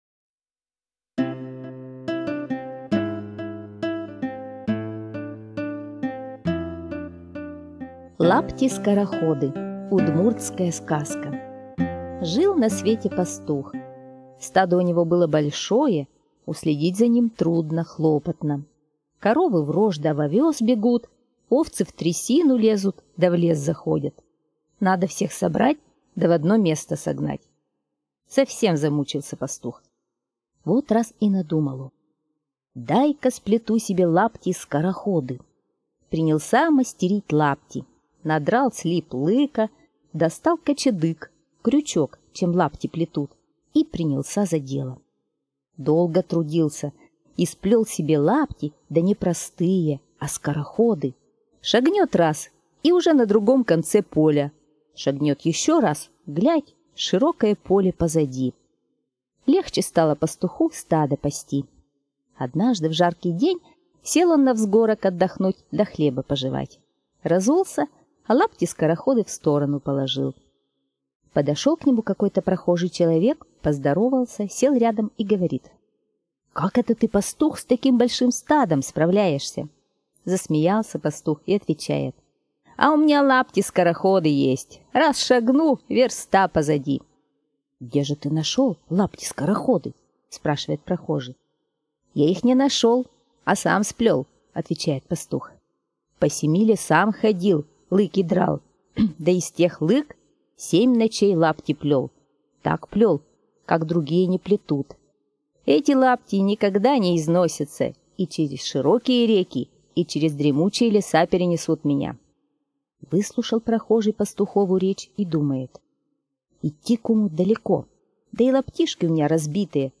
Лапти-скороходы - удмуртская аудиосказка.